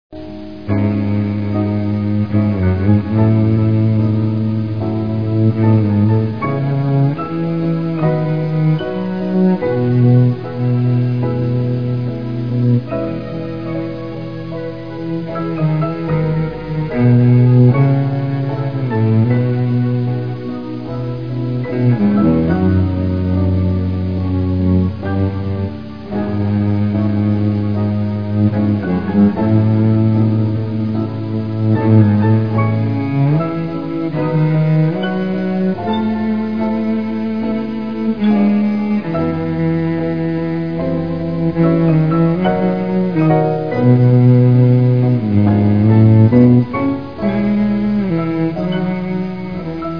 49 seconds of Who Could Be Blue ...bowed !
will play on a Mac's default player (and will play on Windows to.) Almost a meg , but sounds great!, it will take some minutes before it starts to play. (a high speed connection is highly recommended)